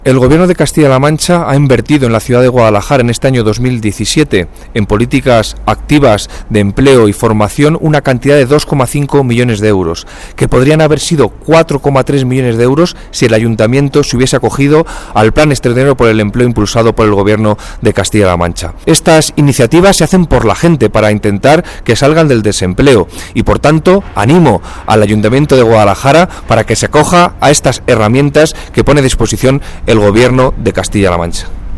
El delegado de la Junta en Guadalajara, Alberto Rojo, habla de la inversión en políticas de empleo en Guadalajara capital.